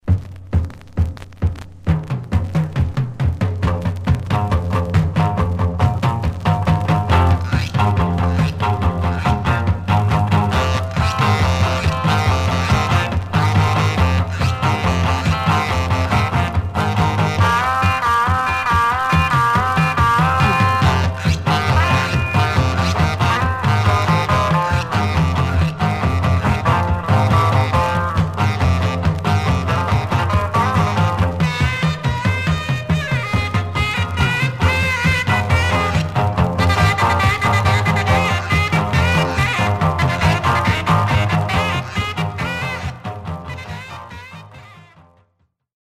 Some surface noise/wear
Mono
R & R Instrumental Condition